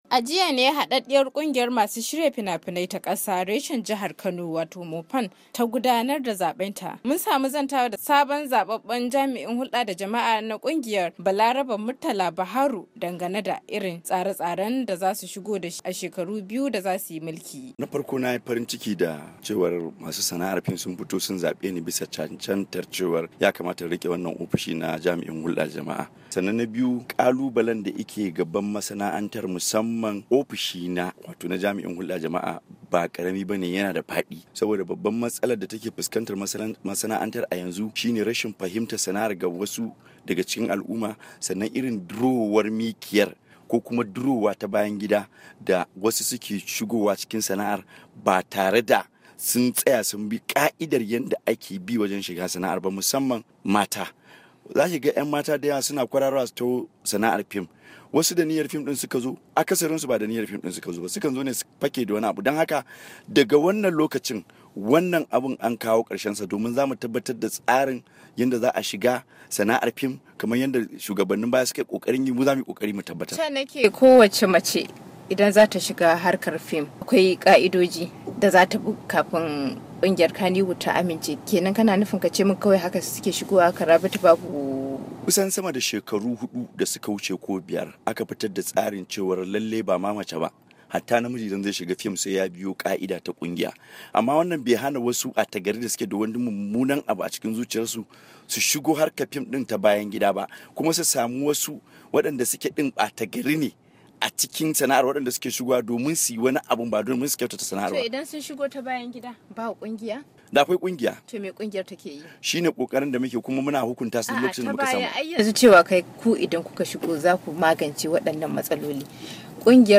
A hira ta mussamam da jami’in hulda da jama’a na kungiyar, ya ce lokaci yayi na fara magance matsalolin da ke addabar masanaantar Kannywood da suka dangance yi wa sana'ar hawan kawara.